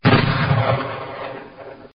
Descarga de Sonidos mp3 Gratis: magnum 44.
44-magnum.mp3